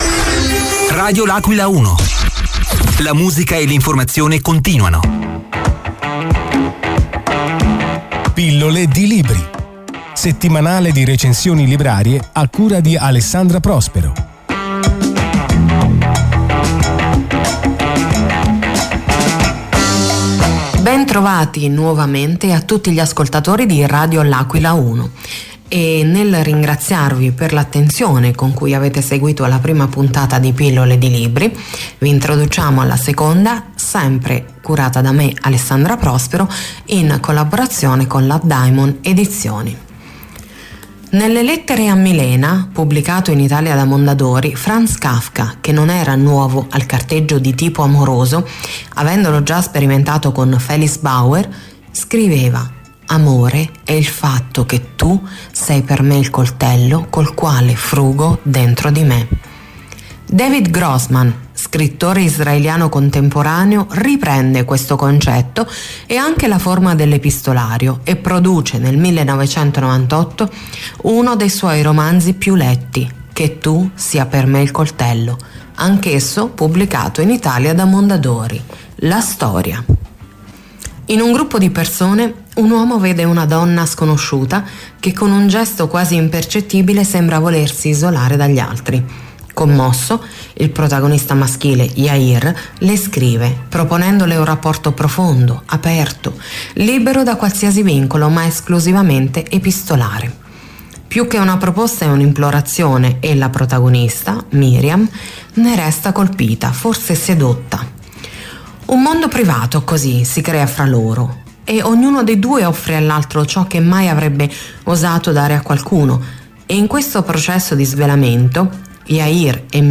la rubrica di recensioni librarie curata dalla giornalista